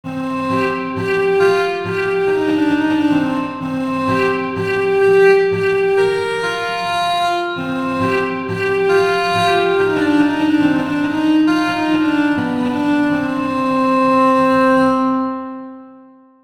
Viola organista
Smyczek przemieszczał się i wydobywał dźwięk ze strun.
Dźwięki instrumentów są brzmieniem orientacyjnym, wygenerowanym w programach:
Kontakt Native Instruments (głównie Factory Library oraz inne biblioteki) oraz Garritan (Aria Player).
Viola-organista.mp3